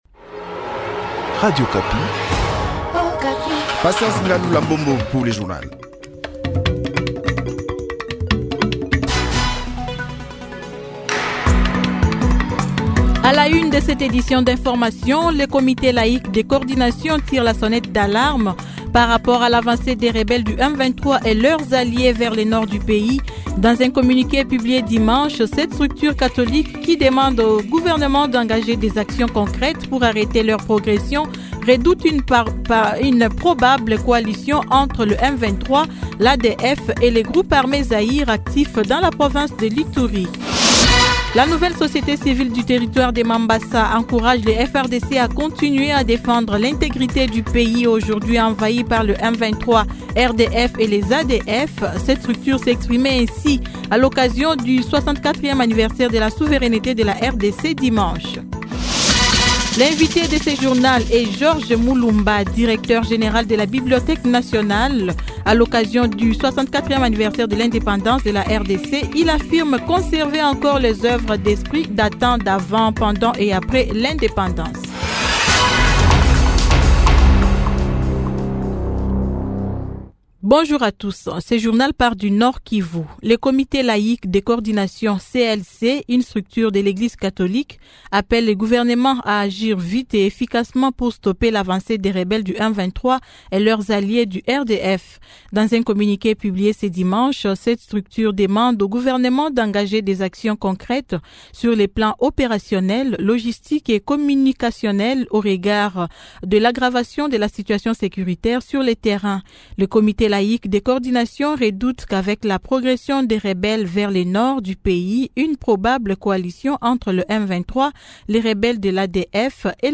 Journal matin 08H